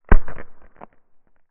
The sound of a Rington's Ginger Snap being broken